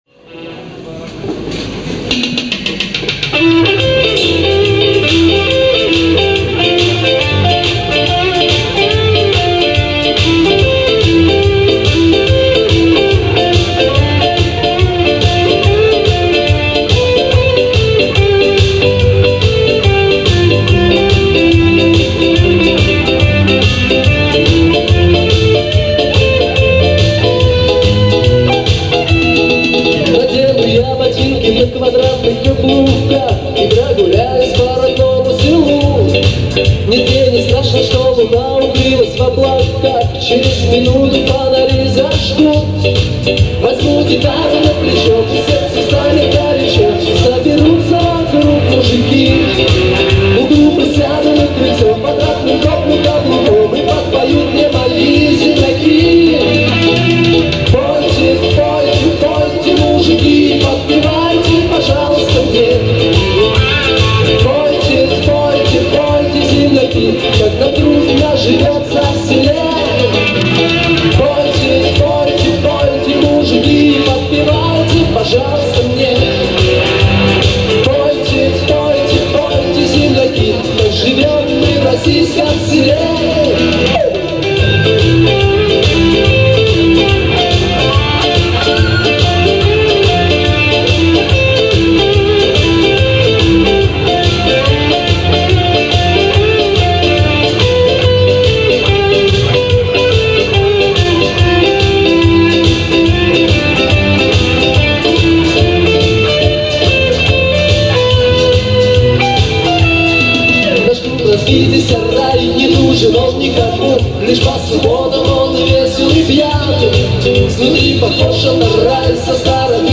Живой концерт - г. Волгореченск